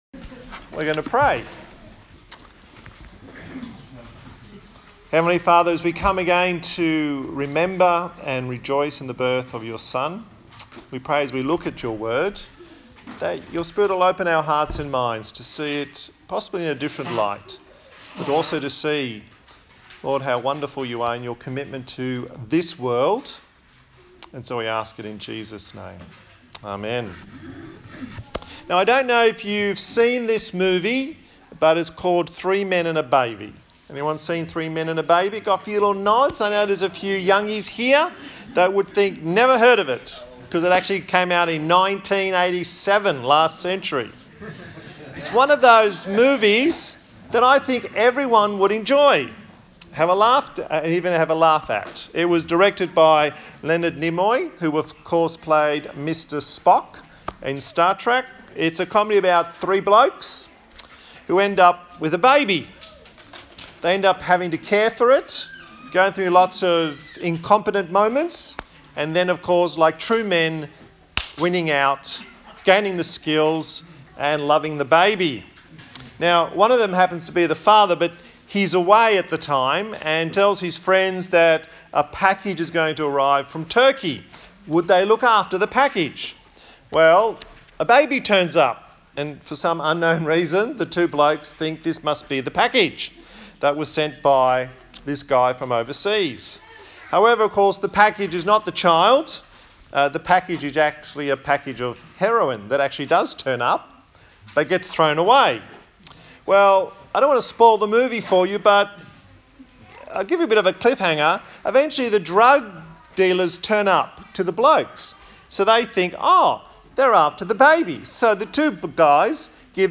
Service Type: Christmas Day